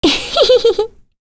Lewsia_A-Vox_Happy1.wav